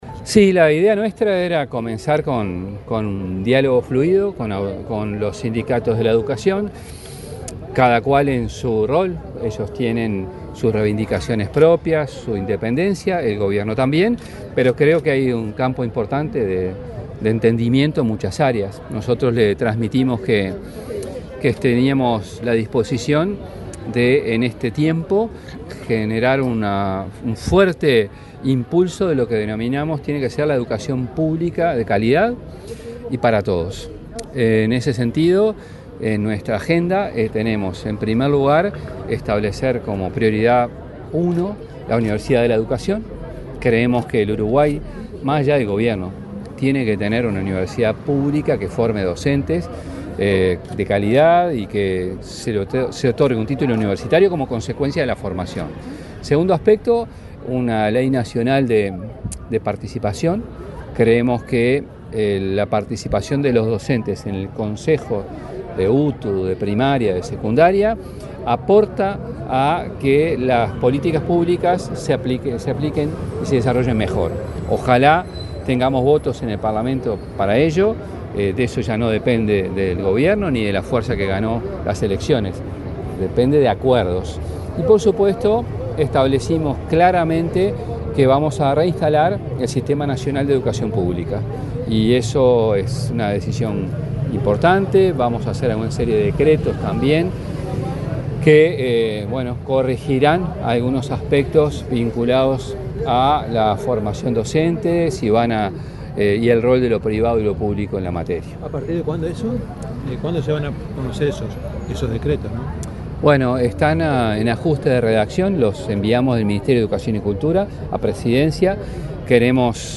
Declaraciones del ministro de Educación y Cultura, José Carlos Mahía
Declaraciones del ministro de Educación y Cultura, José Carlos Mahía 30/04/2025 Compartir Facebook X Copiar enlace WhatsApp LinkedIn Este miércoles 30 en Canelones, el ministro de Educación y Cultura, José Carlos Mahía, informó a la prensa acerca del alcance de las reuniones que ha mantenido con los sindicatos de la educación.